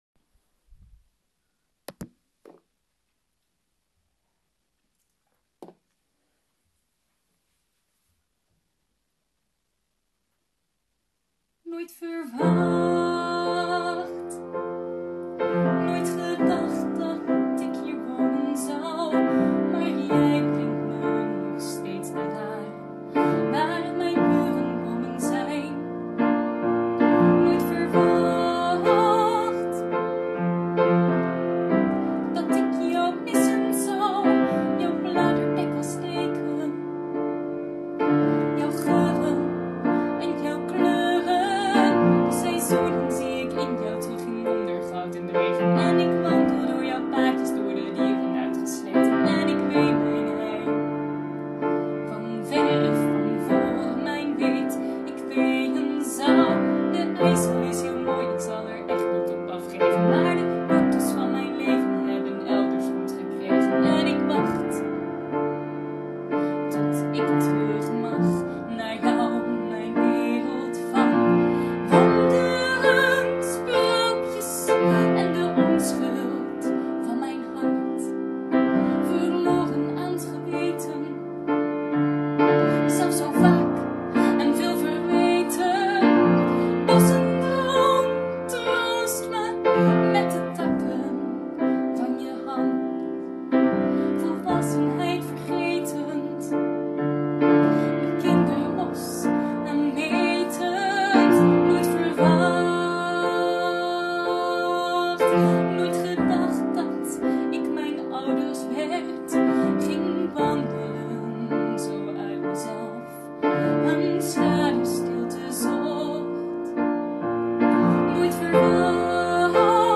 Nu heb ik helaas nooit pianoles gehad waardoor mijn pianospel te wensen overlaat, maar gelukkig speel ik effectief genoeg piano om mezelf met wat akkoorden te kunnen begeleiden. En hoewel ik slechts een oefenopname heb bewaard, waarin de tekst nog een oude versie betreft, het pianospel rammelt, en ik zelf nog meer met de piano dan met het zingen bezig ben, heb ik de geluidsopname hiervan tóch bijgevoegd.